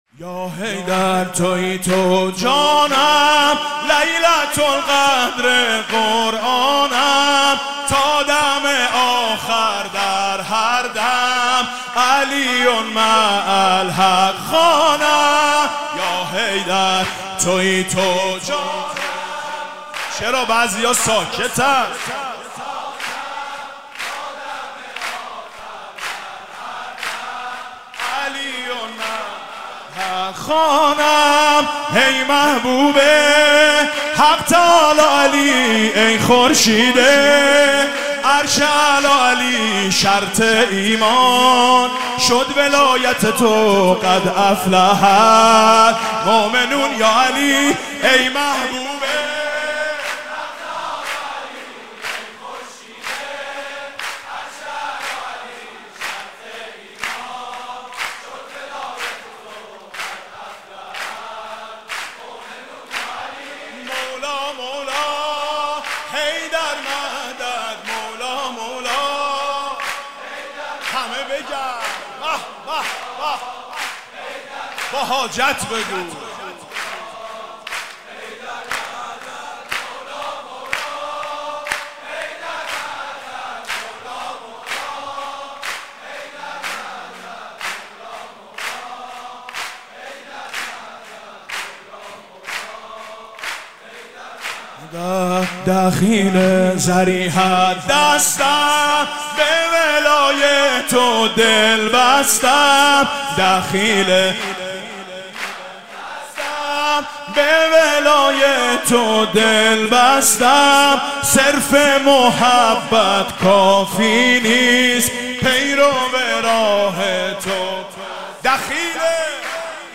سرود: یا حیدر، تویی تو جانم، لیلة القدرِ قرآنم